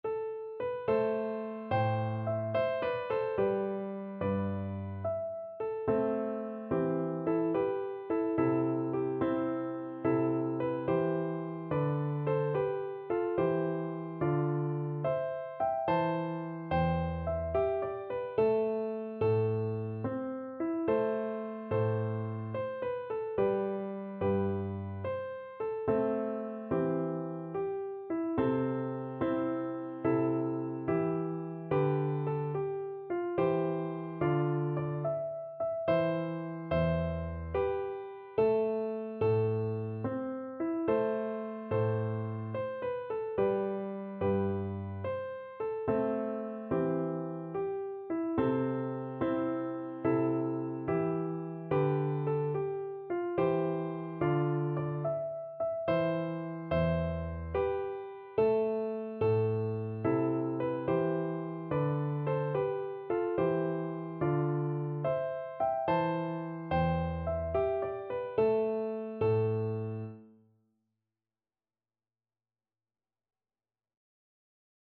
Viola
Gently .=c.72
A minor (Sounding Pitch) (View more A minor Music for Viola )
9/8 (View more 9/8 Music)
Traditional (View more Traditional Viola Music)